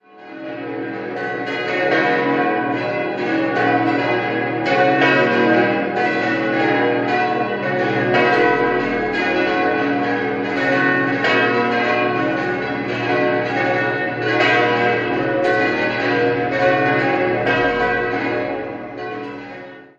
Dieser wurde wiederhergestellt und erhielt um 1908 wieder ein gotisches Aussehen. 6-stimmiges Geläut: h°-cis'-dis'-fis'-gis'-h' Die Glocken wurden 1955 (Nr. 1) und 1947 (alle übrigen) von der Gießerei Grassmayr in Innsbruck gegossen.